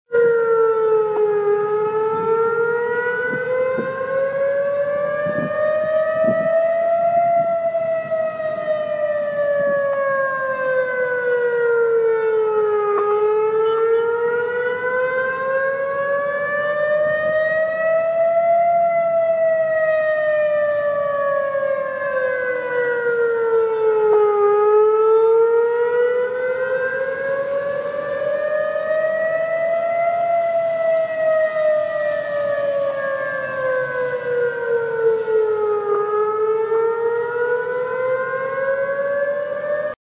Download Emergency Siren sound effect for free.
Emergency Siren